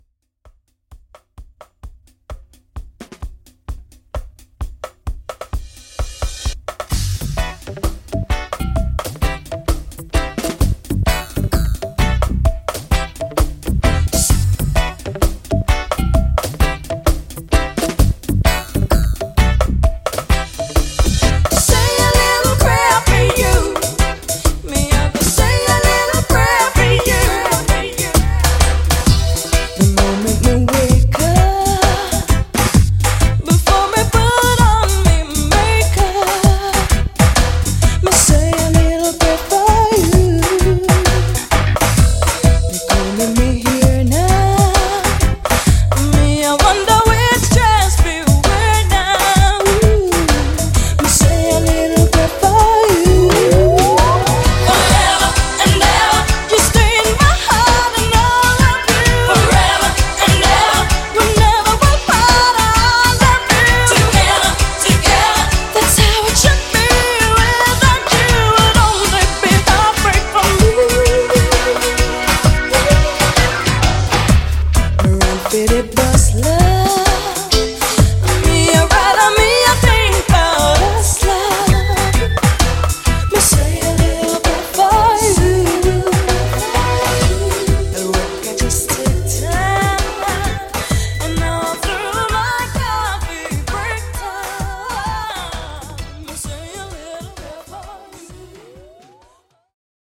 90’s Pop Reggae)Date Added